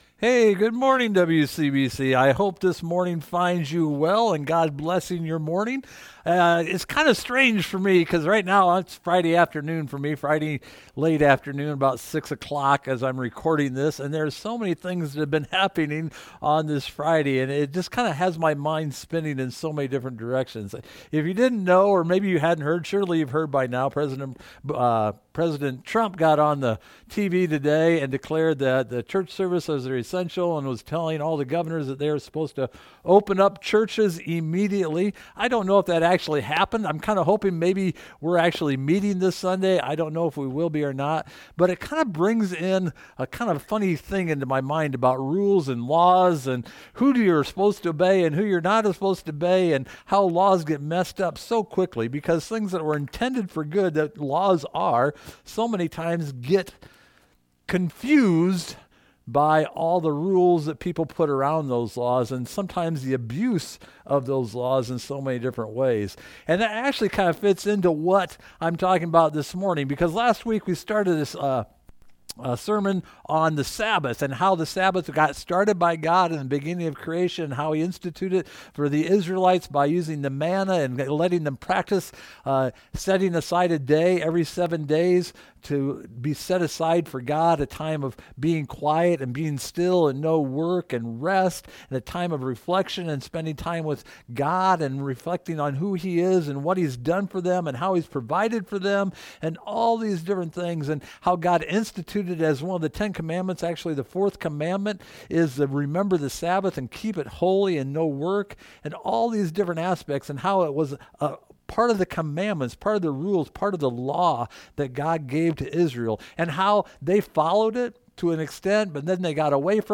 Service Type: Online Message